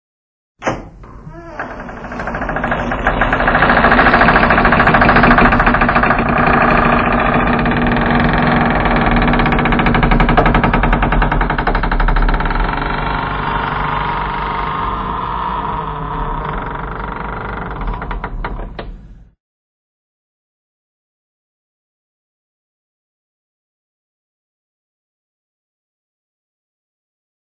Звуки скрипа двери
Жуткий скрип медленно открывающейся двери